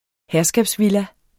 Udtale [ ˈhæɐ̯sgabs- ]